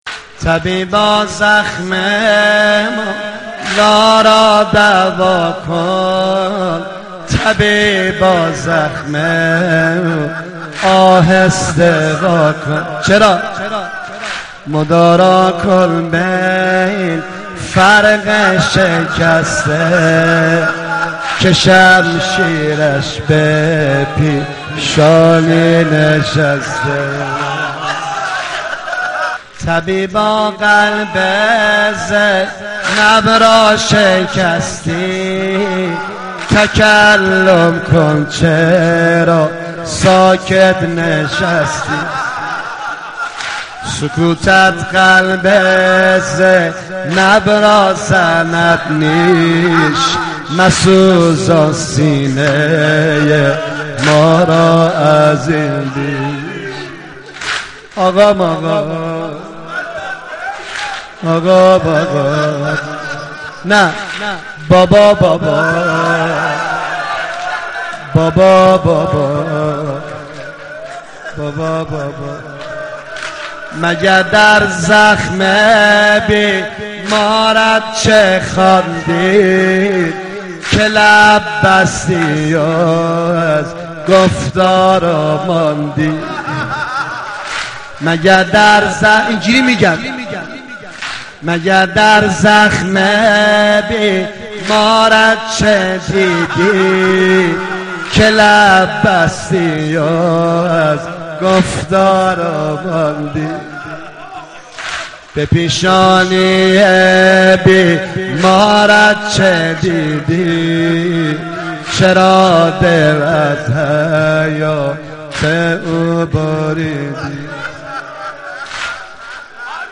رمضان 89 - سینه زنی 3
رمضان 89 - سینه زنی 3 خطیب: نریمان پناهی مدت زمان: 00:05:45